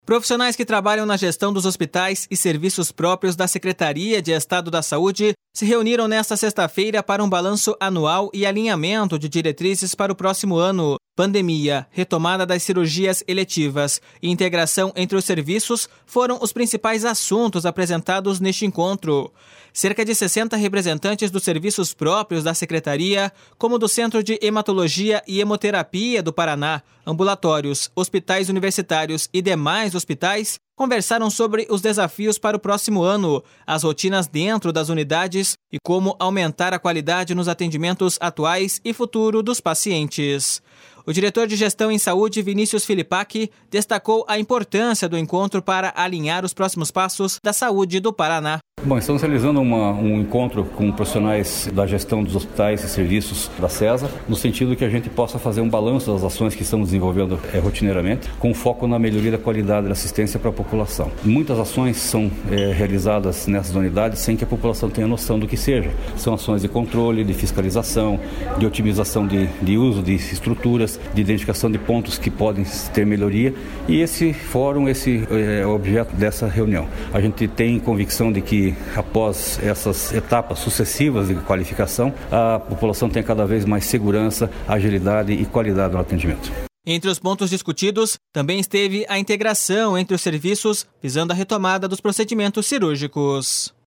O diretor de Gestão em Saúde, Vinícius Filipak, destacou a importância do encontro para alinhar os próximos passos da saúde do Paraná.// SONORA VINÍCIUS FILIPAK.//